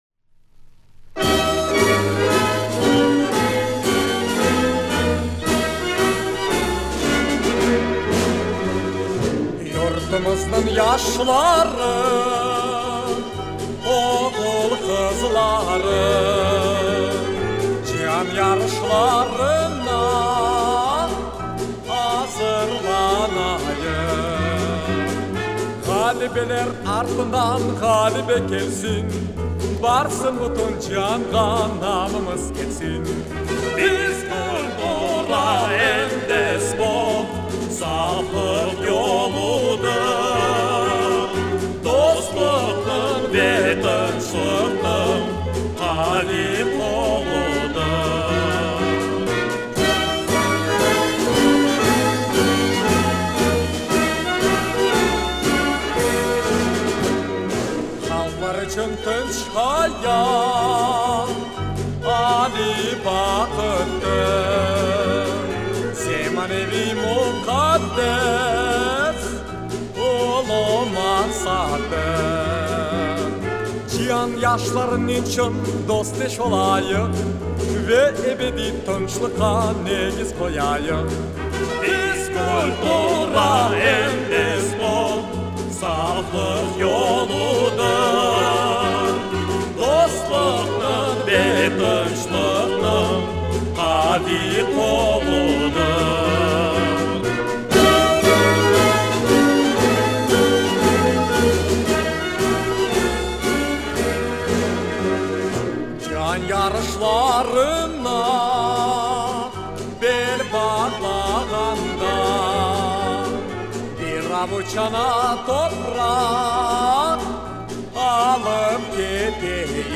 Описание: Крымско-татарская песня.